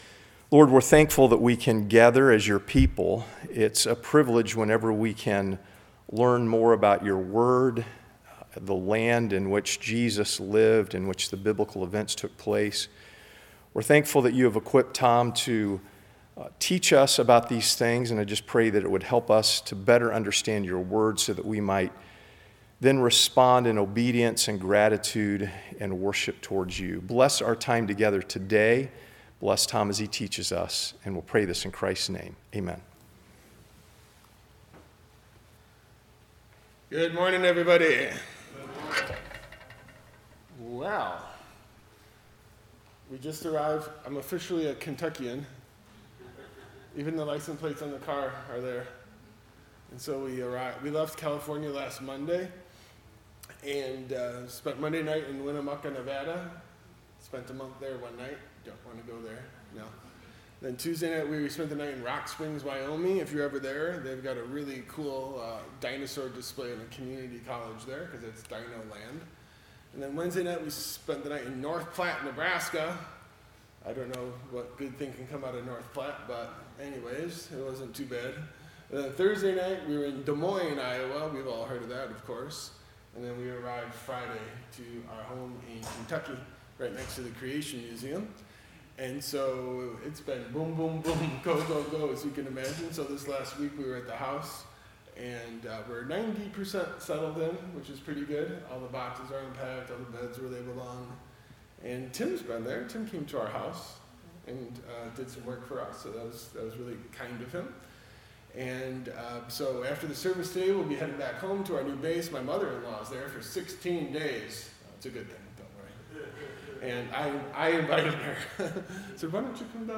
Sunday School Prophecy Study